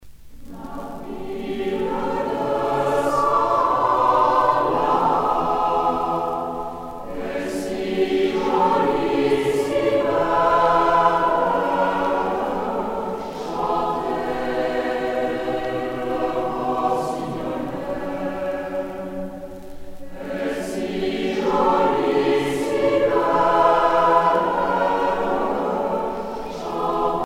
Genre laisse Artiste de l'album Franco-Allemande de Paris (chorale)